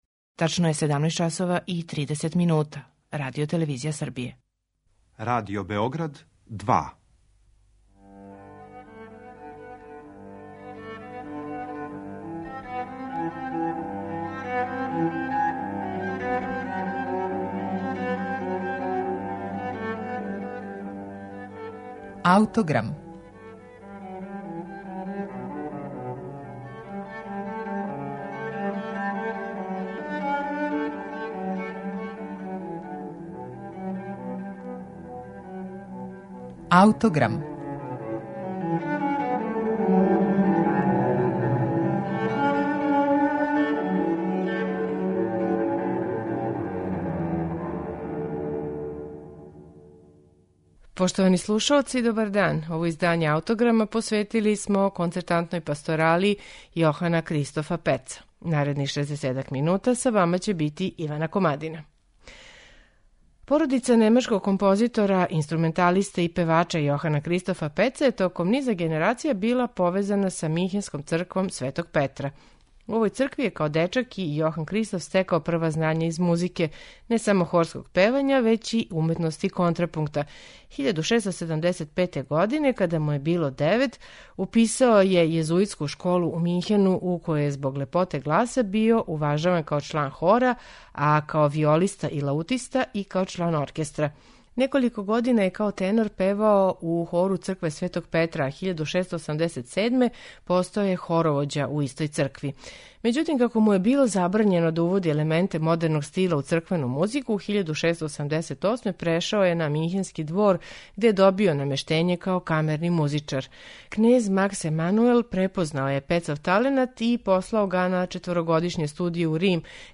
Концертантна пасторала у Ф-дуру је специфична свита од седам ставова, у којој се смењују пасторале и арије. Претпоставља се да је била намењена извођењу на Божић. У данашњем Аутограму слушаћемо је у интерпретацији ансамбла Il Giardino Armonico, под управом Ђованија Антонинија.